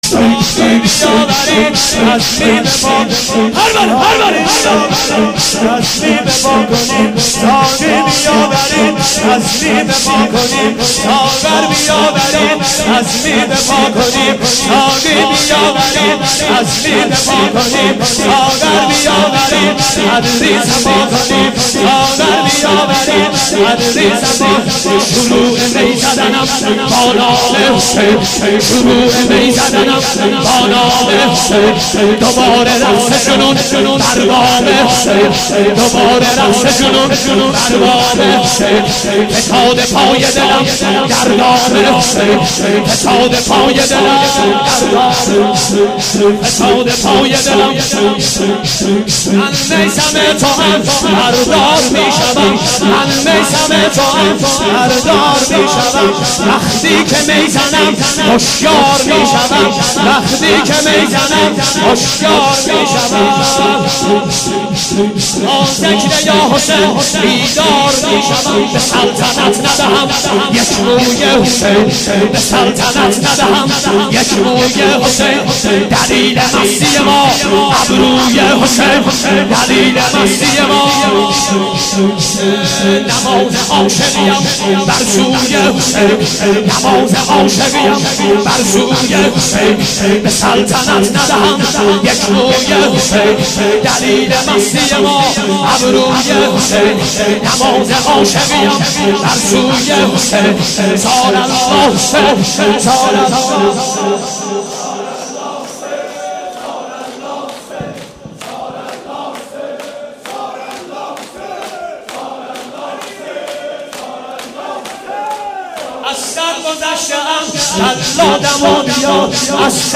مداحي